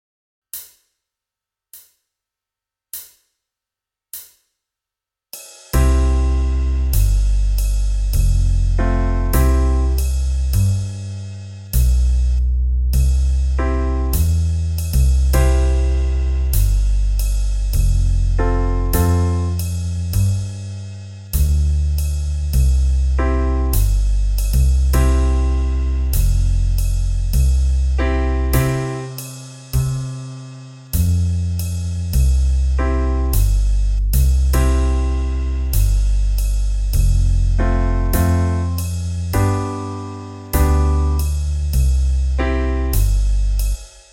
Der Song ist in einem langsamen Tempo aufgenommen, im zweiten Sound ist nur die Begleitung zu hören - "Playalong". Die Instrumentierung wurde so gewählt dass sich der Klang möglichst wenig mit dem des eigenen Instrumentes vermischt.
for-hes-a-jolly-good-fellow-band.mp3